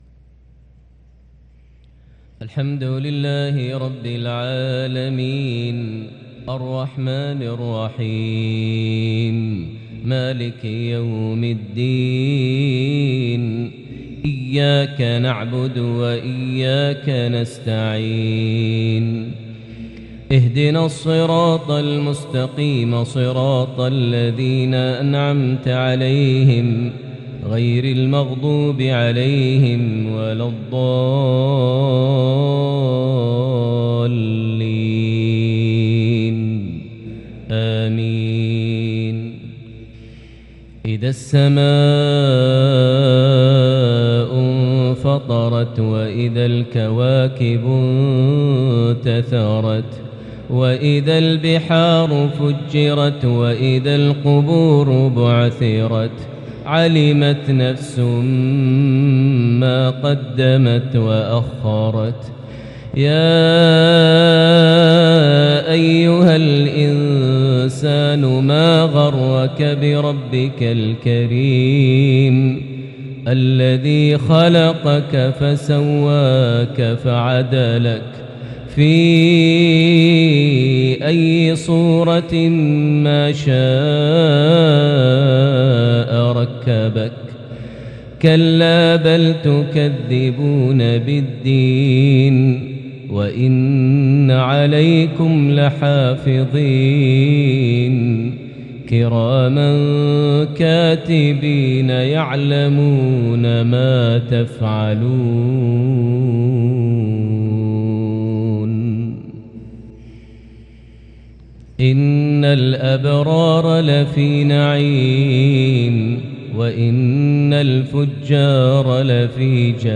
صلاة المغرب سورة الإنفطار | 7 شوال 1443هـ| maghrib 8-5-2022 prayer from Surah AL-Infitar > 1443 🕋 > الفروض - تلاوات الحرمين